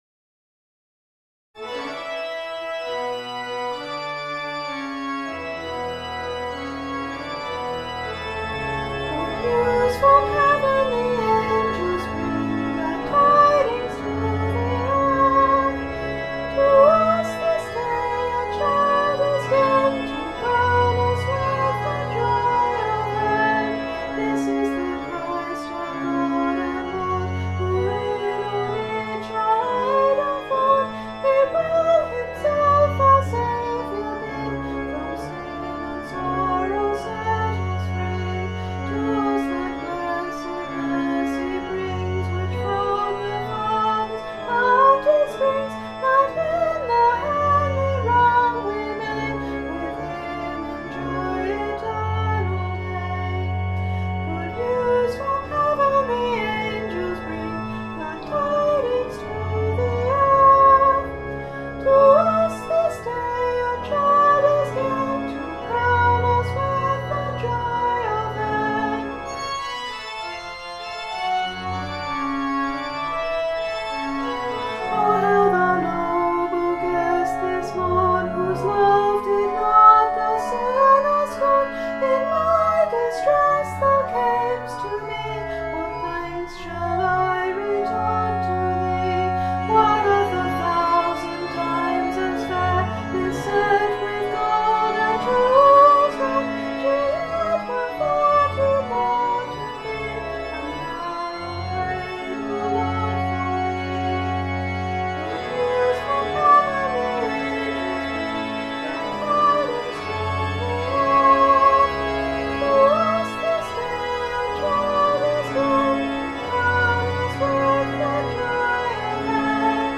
My 2012 Christmas carol!
It's got me singing. To a backing track of massive organ and trumpet playing the descant!) so there's still quite a bit of work to do on it.